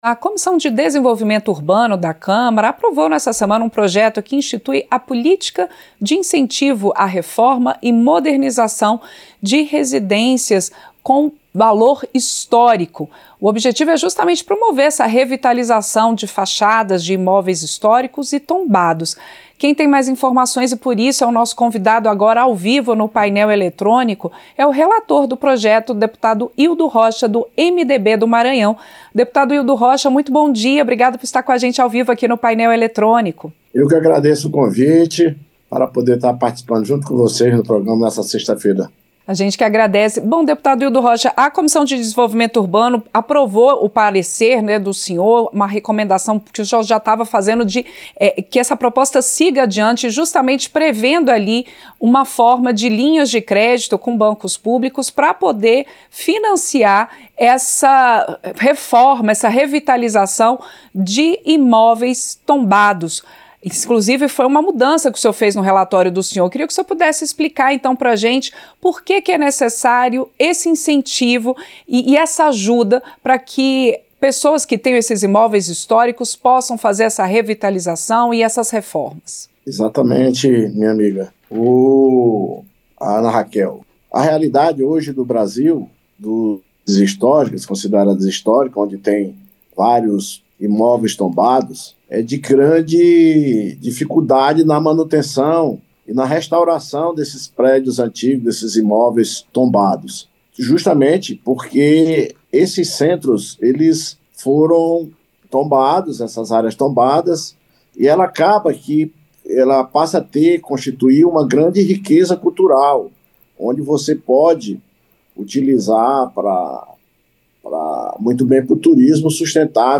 Entrevista - Dep. Hildo Rocha (MDB-MA)